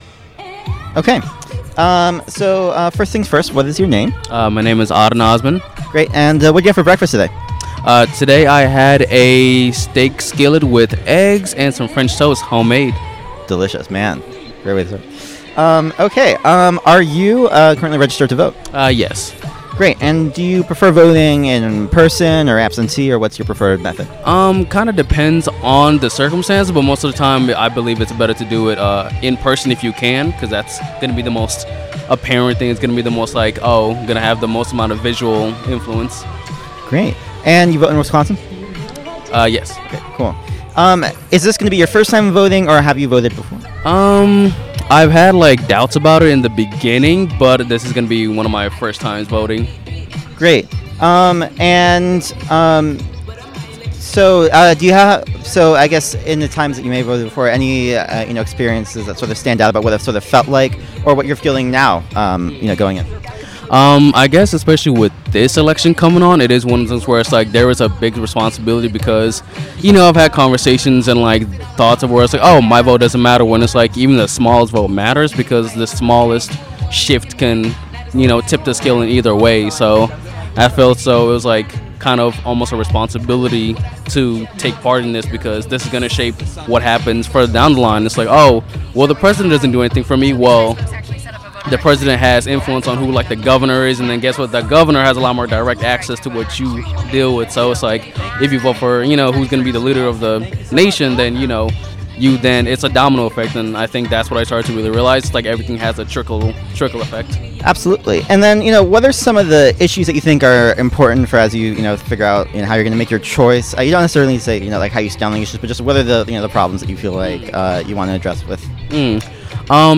Location University of Wisconsin-Milwaukee